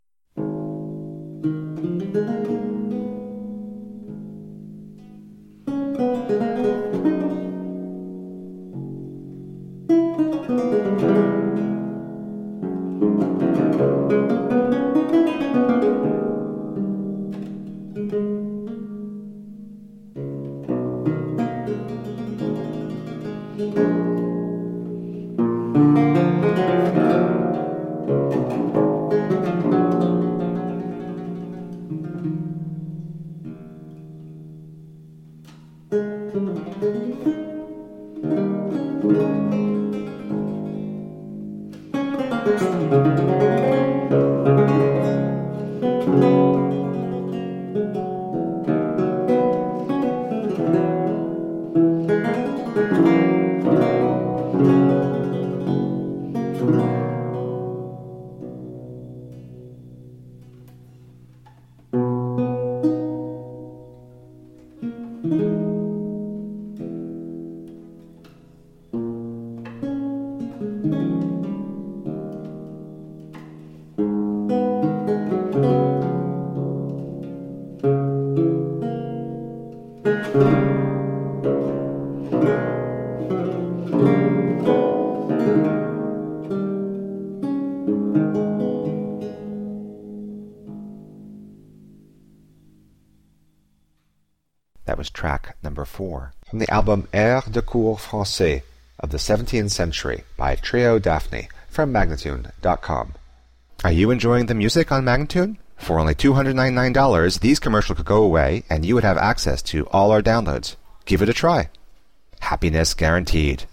Delicate 17th century chansons.
the three women
with all-original instrumentation
with the intimate feeling of a parlor room recital.